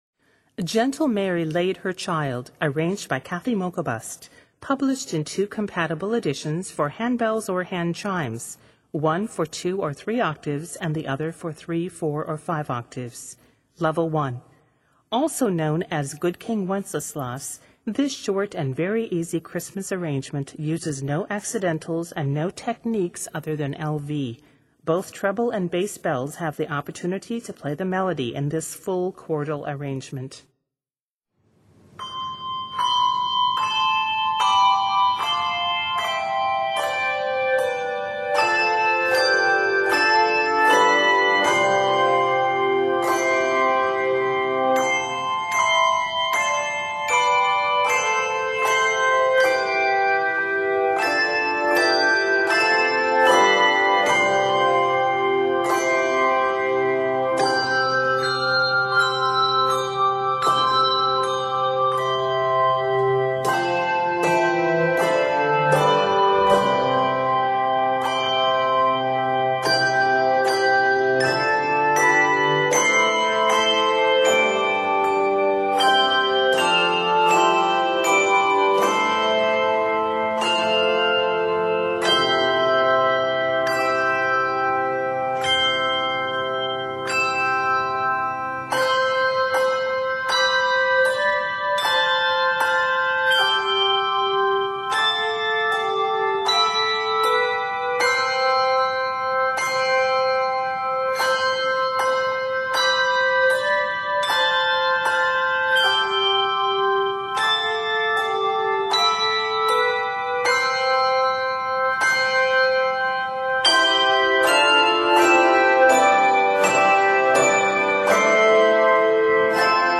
Set in C Major, this work is 39 measures.